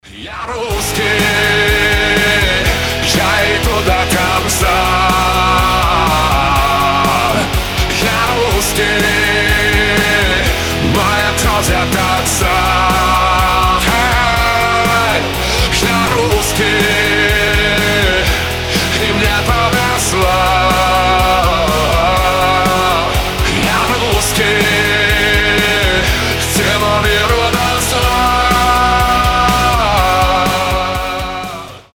Рок рингтоны
mashup